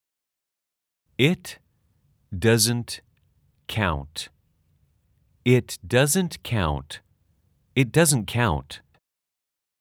/ 잇 더즌 카아운트 /
(3회 반복 연습:아주 느리게-느리게-빠르게)